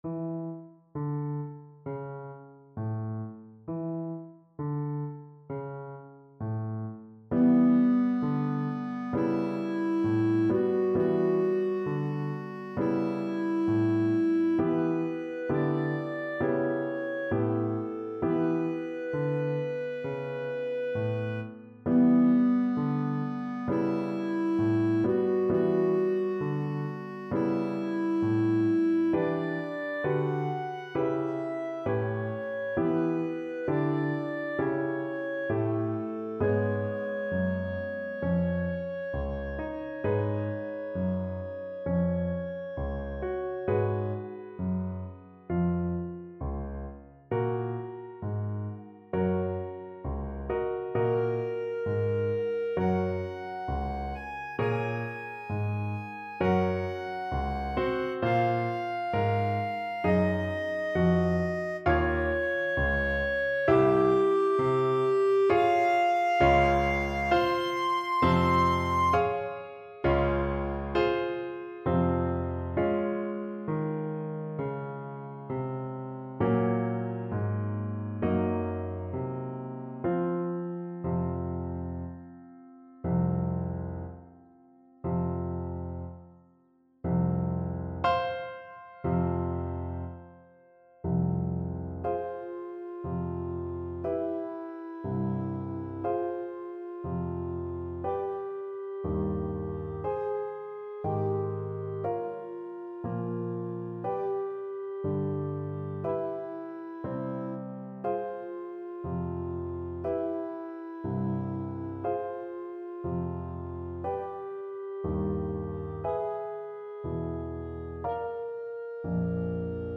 Free Sheet music for Clarinet
Clarinet
4/4 (View more 4/4 Music)
F major (Sounding Pitch) G major (Clarinet in Bb) (View more F major Music for Clarinet )
Poco animato. = c.66
Classical (View more Classical Clarinet Music)